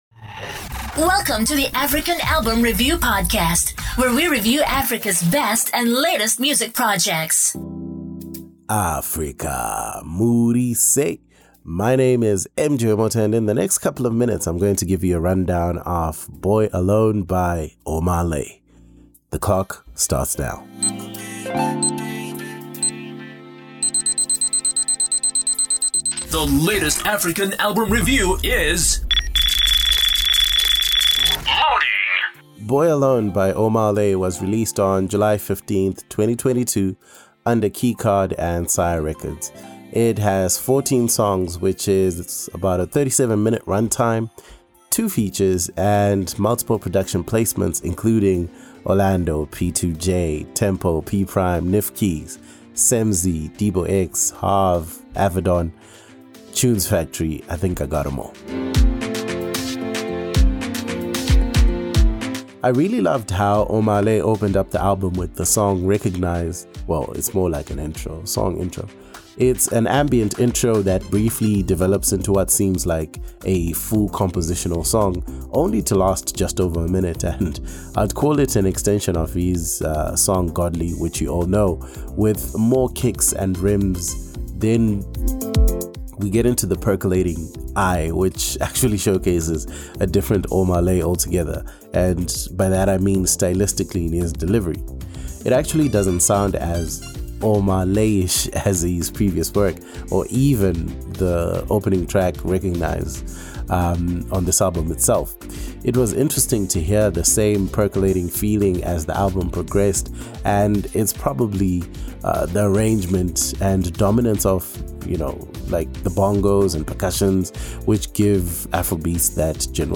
Music commentary and analysis on African albums